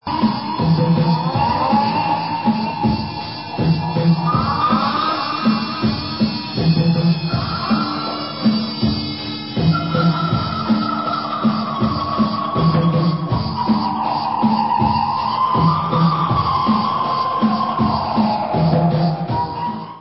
Dance/Techno